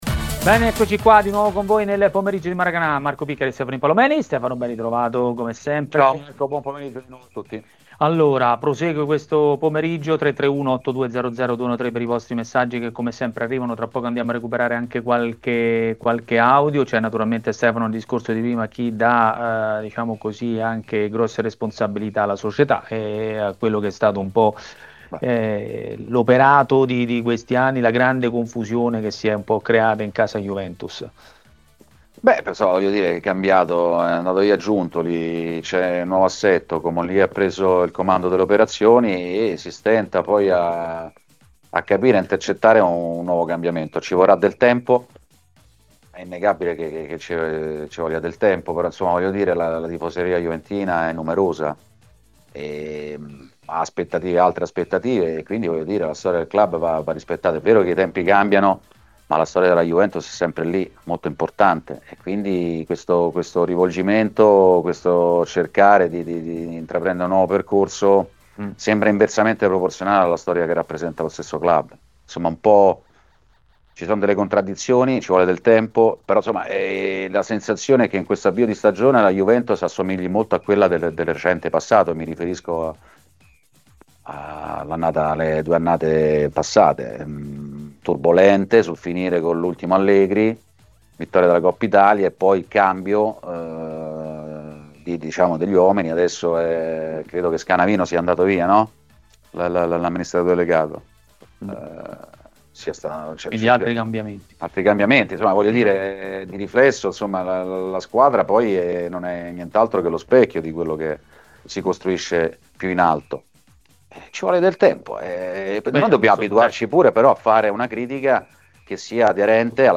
Ospite di TMW Radio, durante Maracanà, è stato mister Luigi De Canio.